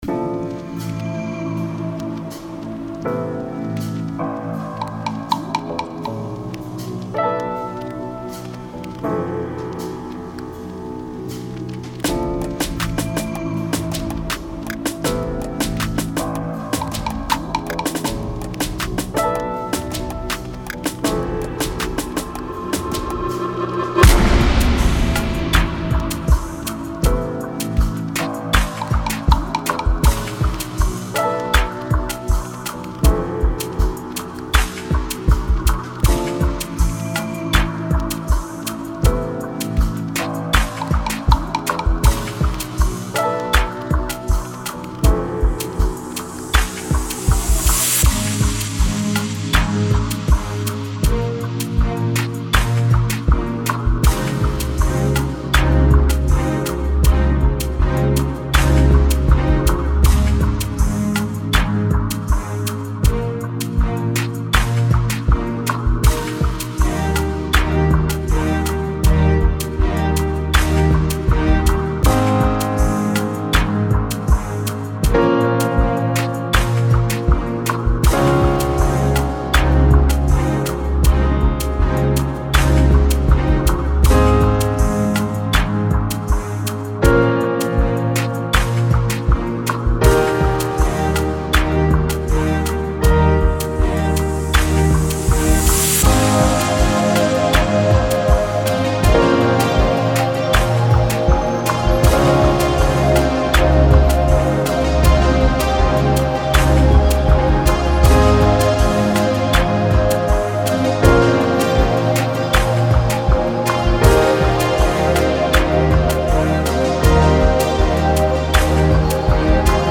Expect vibe changes. This is the instrumental.
Tempo 80BPM (Andante)
Genre Slow Energy Amapiano
Type Instrumental
Mood energetic to Chill